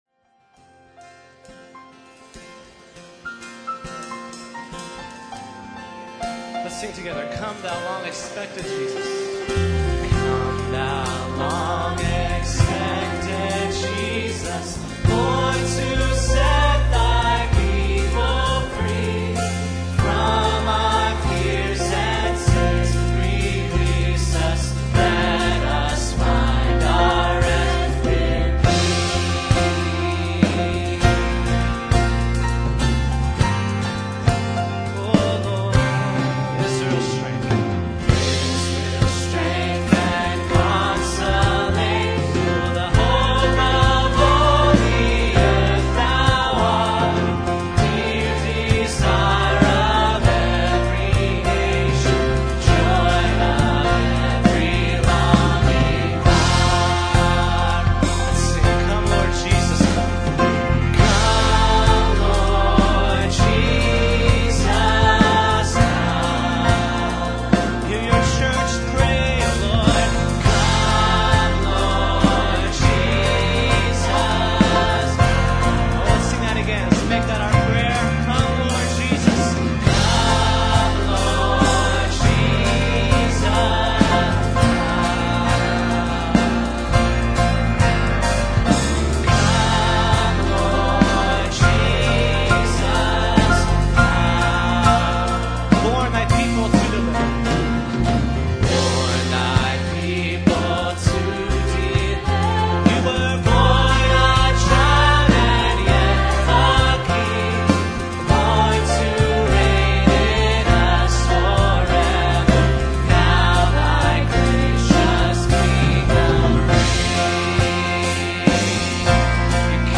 Sometimes I’ll give this hymn a driving 4/4 beat, and in between some of the verses I’ll use the refrain from Brenton Brown’s “All Who Are Thirsy” that says “come, Lord Jesus, come”. Here is a really rough recording (i.e. sound board mix – reduced quality for space reasons – with mistakes – never intended to be posted on the internet…) of us doing this at my church in 2006.